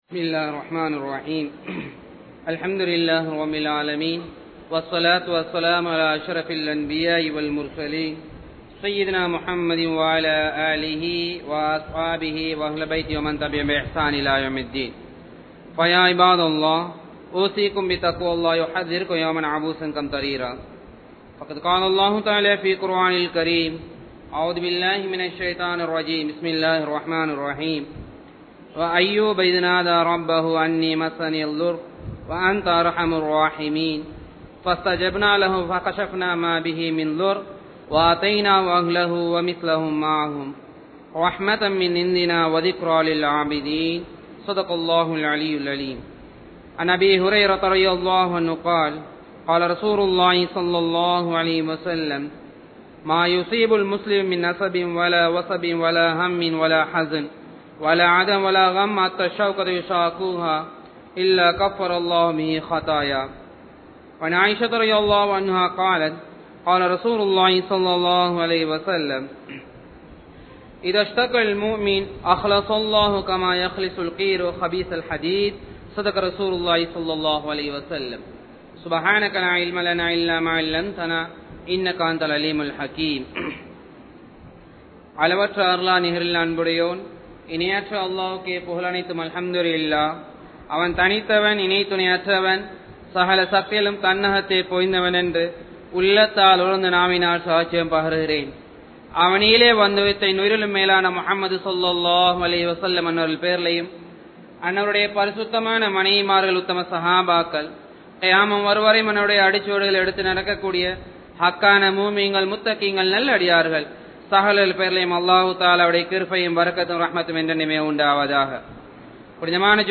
Islamiya Paarvaiel Noai (இஸ்லாமிய பார்வையில் நோய்) | Audio Bayans | All Ceylon Muslim Youth Community | Addalaichenai
Wellampittiya, Sedhawatte, Ar Rahmath Jumua Masjidh